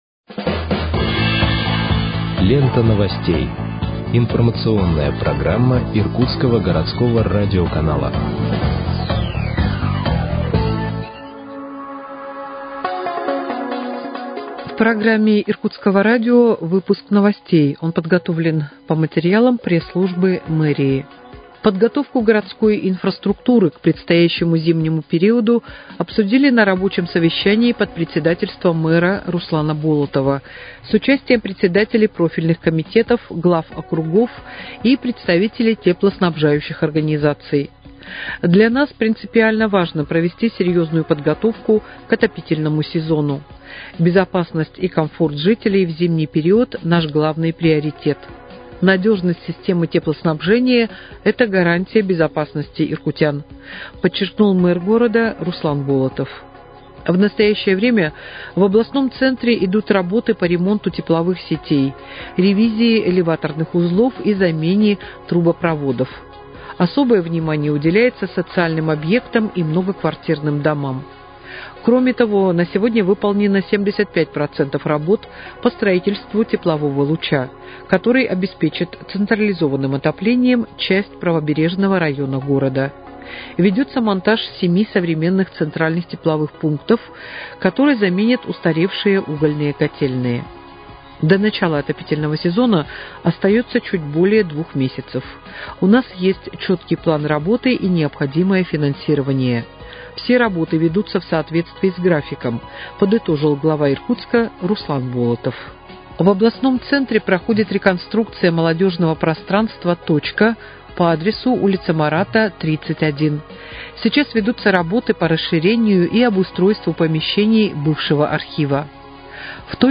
Выпуск новостей в подкастах газеты «Иркутск» от 15.07.2025 № 2